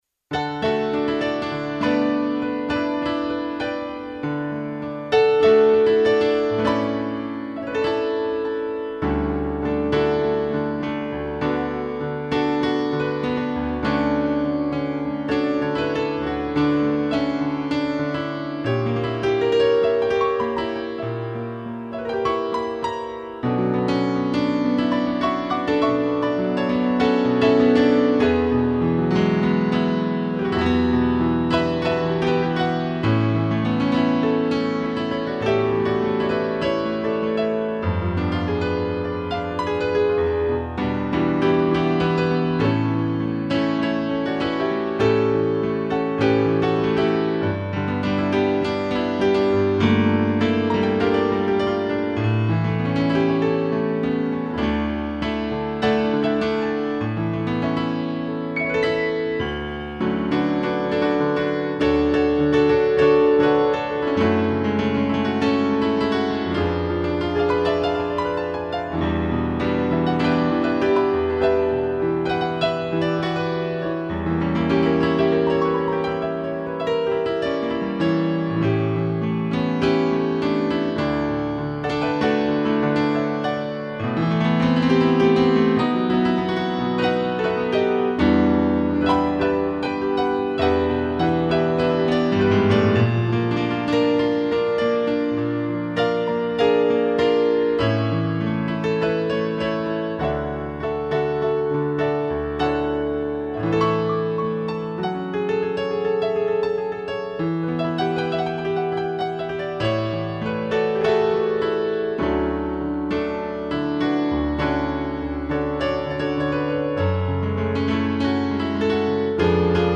2 pianos e cello
(instrumental)